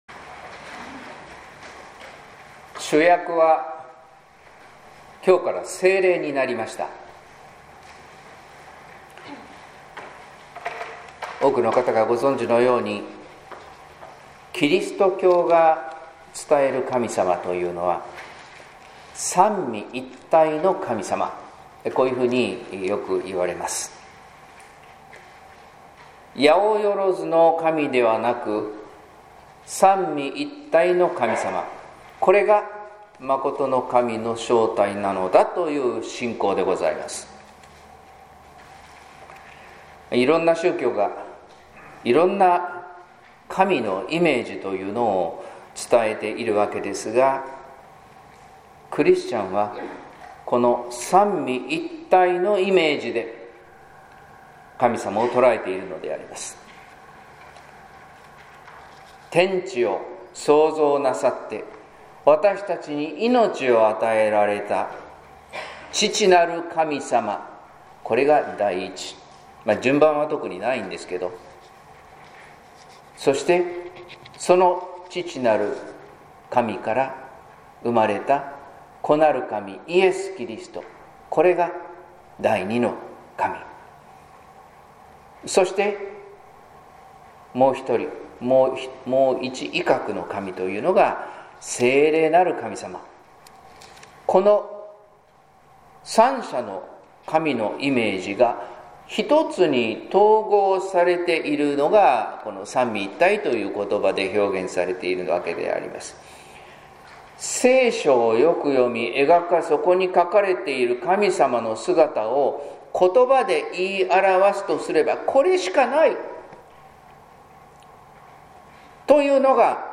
説教「大胆、静かに聖霊働く」（音声版） | 日本福音ルーテル市ヶ谷教会